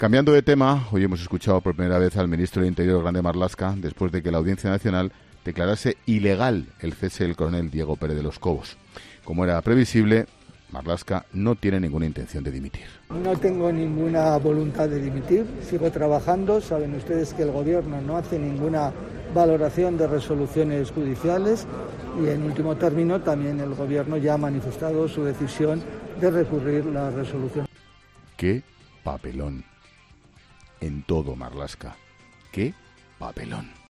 El presentador de 'La Linterna' comenta la primera intervención del ministro del Interior en plena polémica renovada por el cese del coronel Pérez de los Cobos